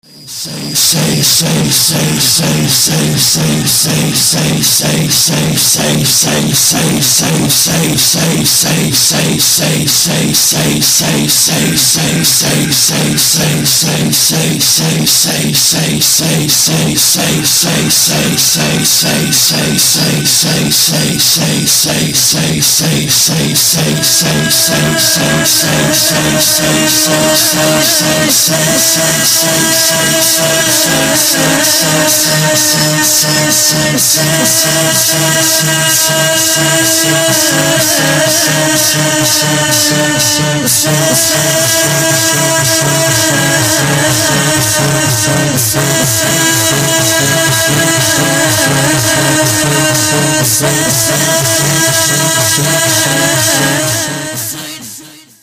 ذکر